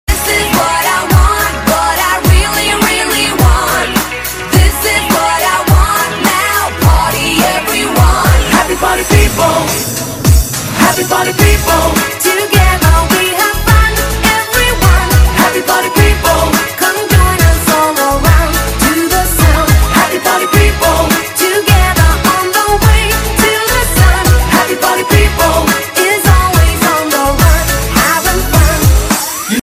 DJ舞曲